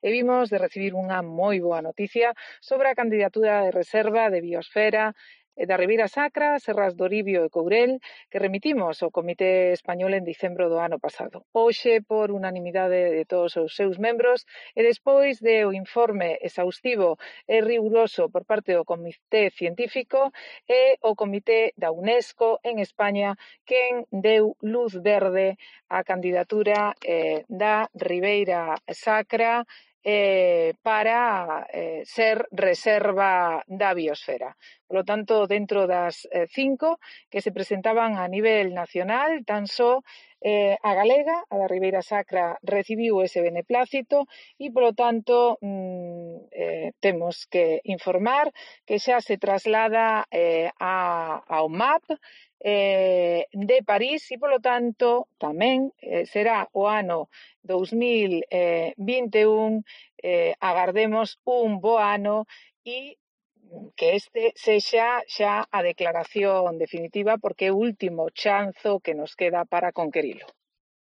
Declaraciones de Ángeles Vázquez sobre la cndidatura de la Ribeira Sacra